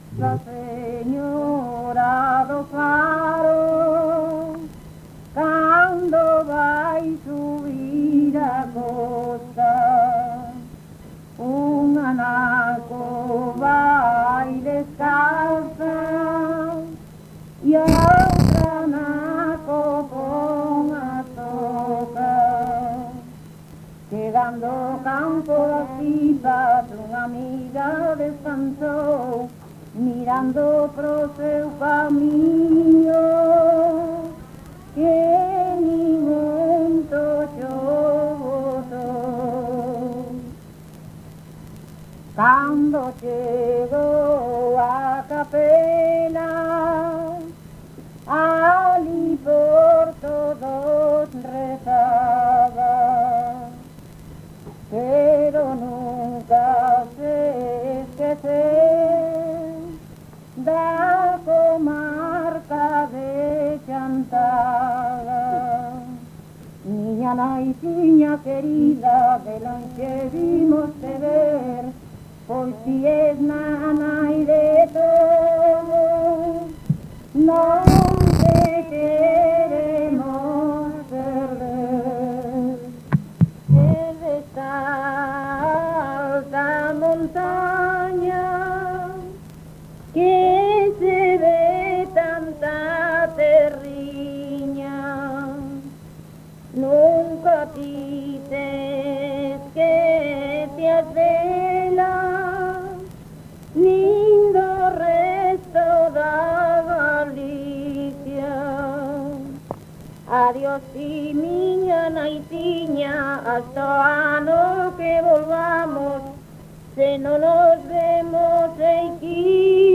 Concello: Chantada.
Palabras chave: oración local
Áreas de coñecemento: LITERATURA E DITOS POPULARES > Cantos narrativos
Soporte orixinal: Casete
Instrumentación: Voz
Instrumentos: Voz feminina